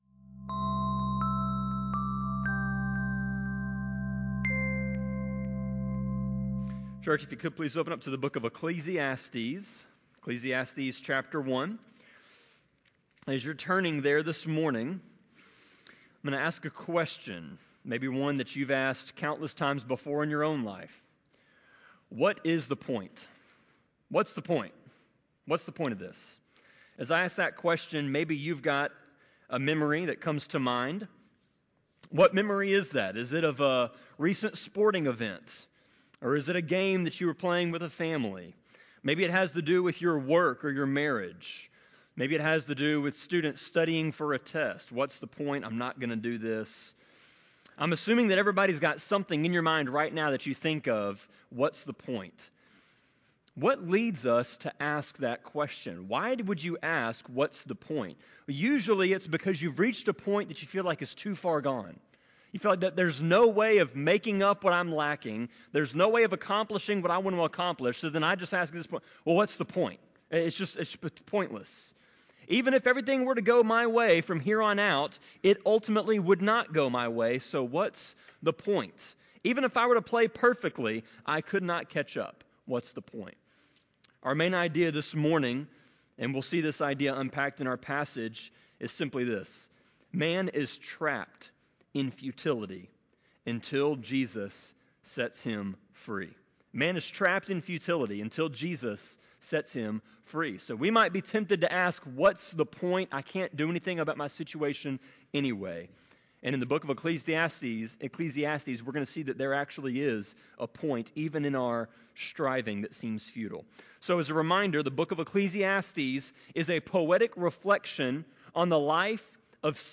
Sermon-24.11.3-CD.mp3